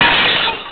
snd_7993_BrokenGlass.wav